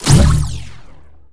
sci-fi_weapon_blaster_laser_boom_03.wav